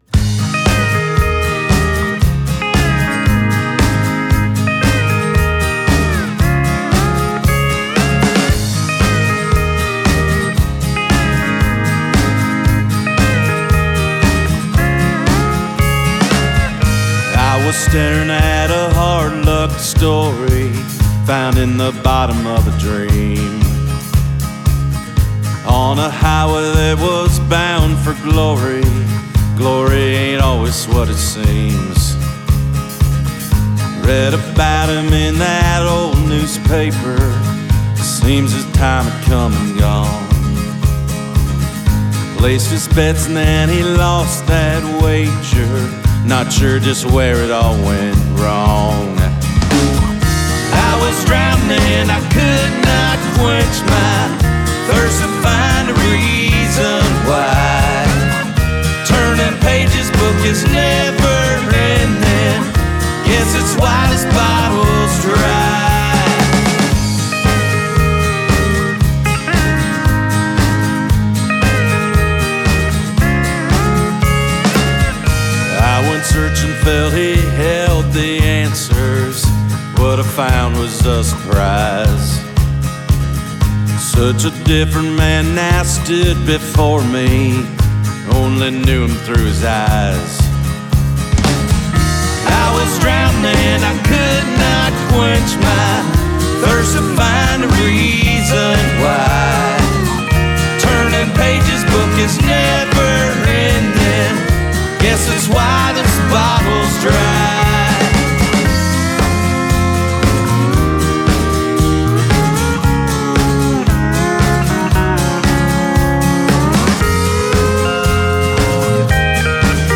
Original Americana From The Heartland